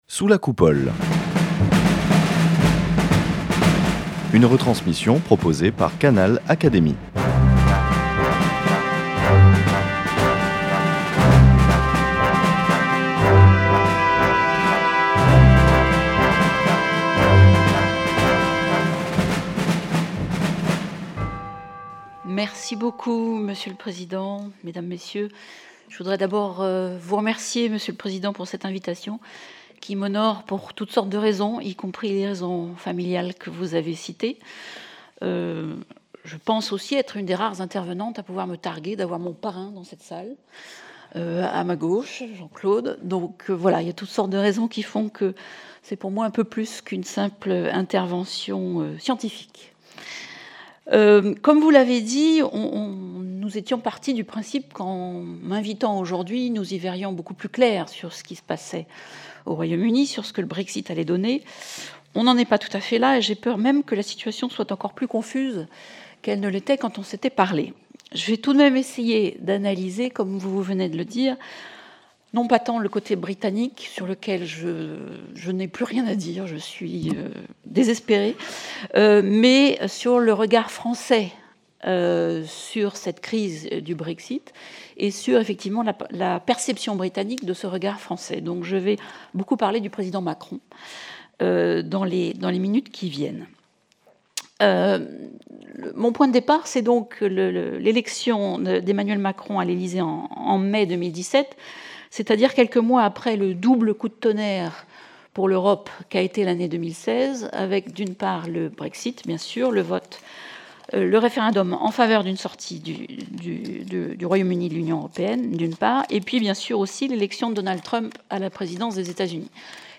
L’oratrice a notamment a notamment souligné que si Londres espérait s’appuyer sur des relations bilatérales privilégiées avec Paris pour négocier des conditions de retrait favorables, cet espoir s’est révélé vain au fil des mois, déjoué par un front uni des 27 qui s’est révélé plus solide que prévu et par l’affermissement de la position française.